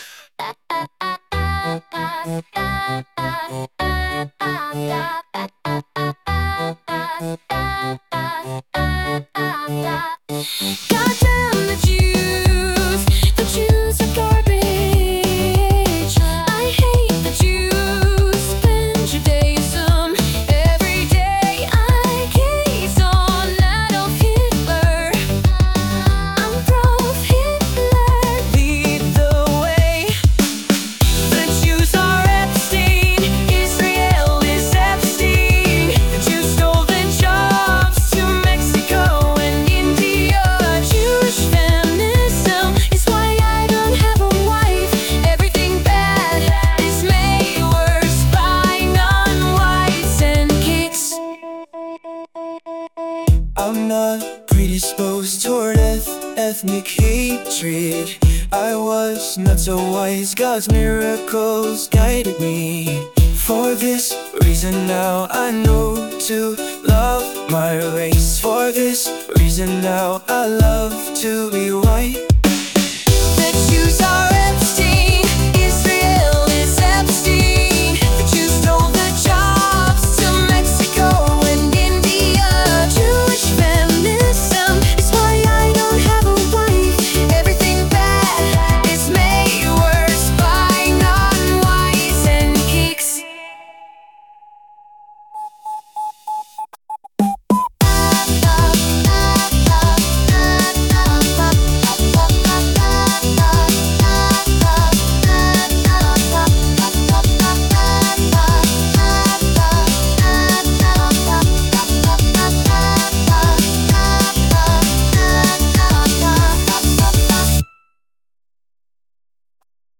style prompt: teen pop